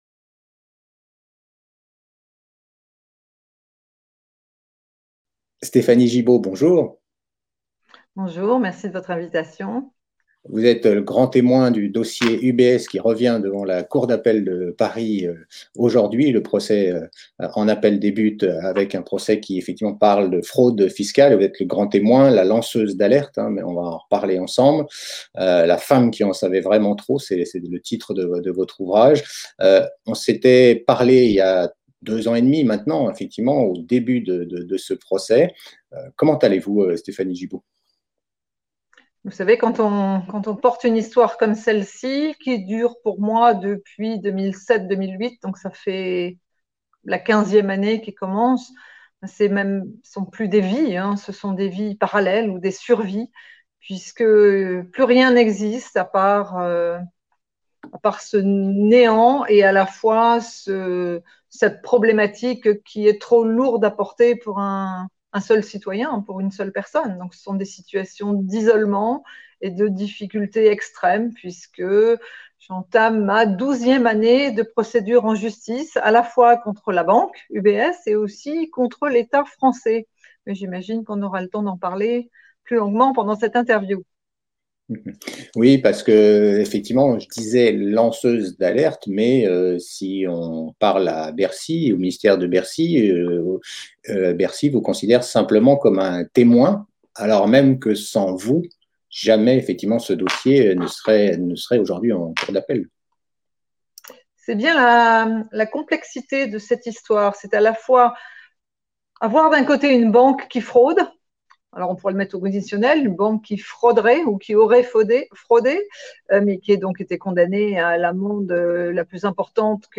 interview-stephanie-gibaud-ubs-8-mars-2021.mp3